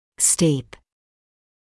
[stiːp][стиːп]крутой; расположенный вертикально под большим углом